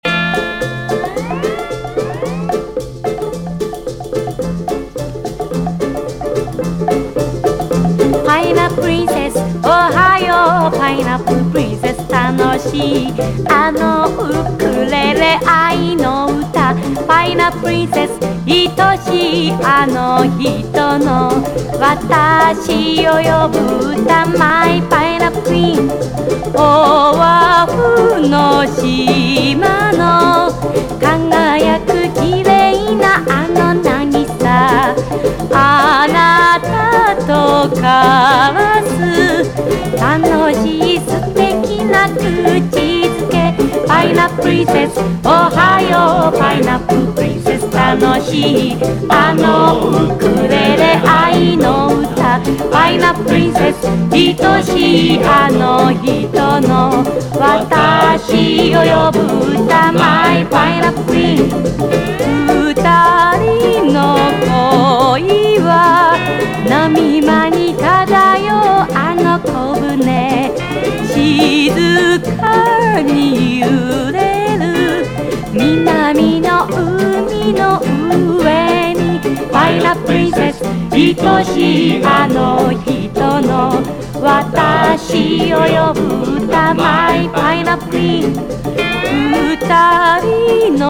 JAPANESE OLIDIES
昭和ダンスパーティーなグレイト・コンピ！ 洋楽日本語カヴァーを数多く収録したザ・昭和ダンスなコンピレーション！